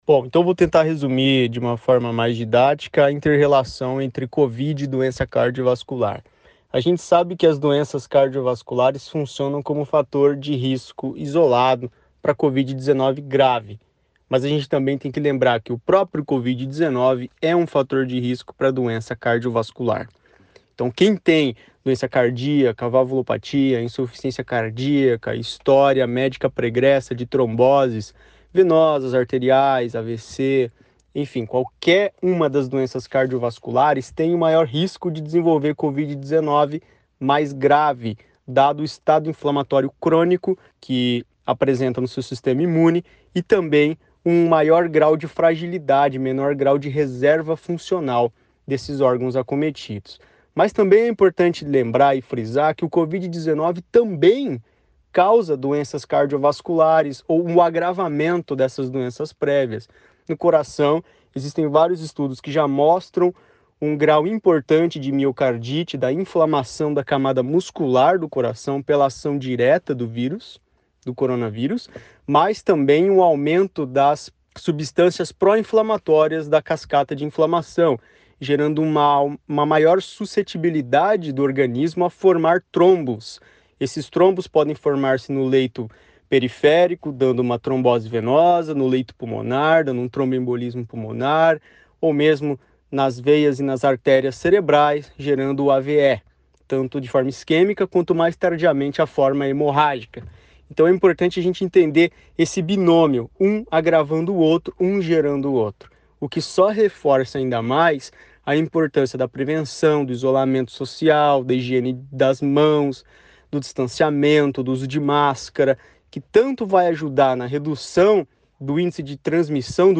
Setembro Vermelho: Médico fala sobre doenças cardiovasculares e Covid-19